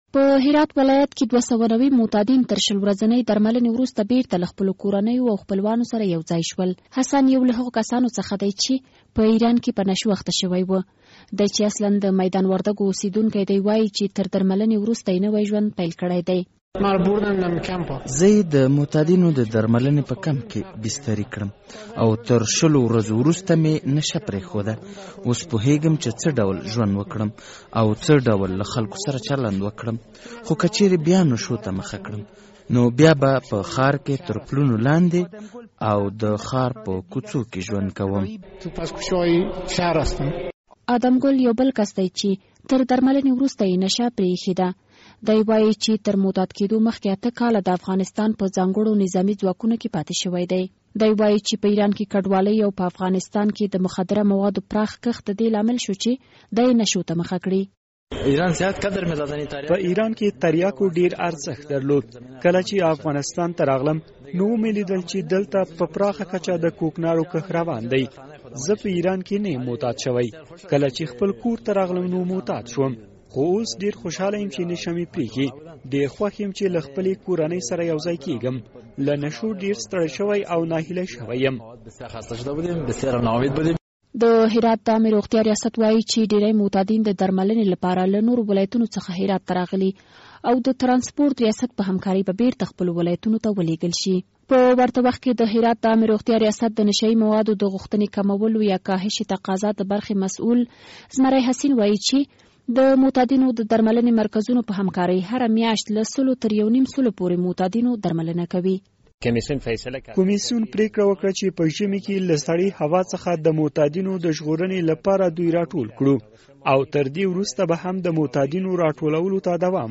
د زهرو کاروان فیچر
راپور